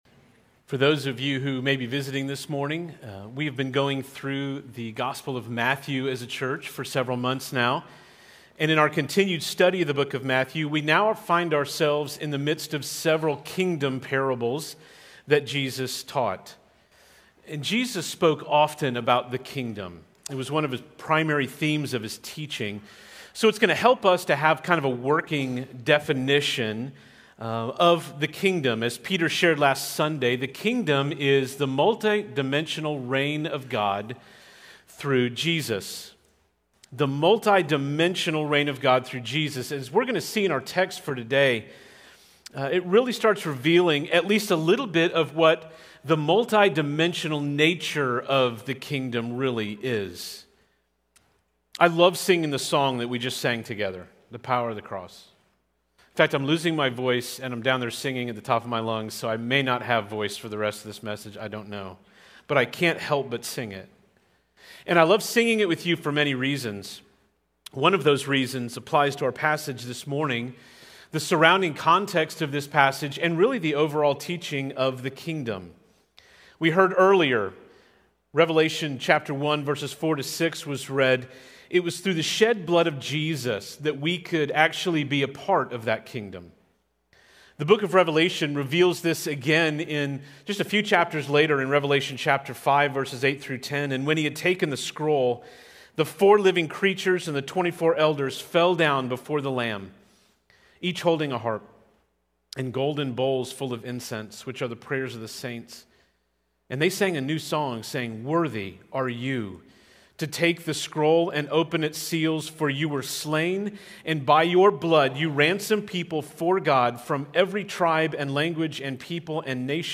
Prev Previous Sermon Next Sermon Next Title The Unstoppable Kingdom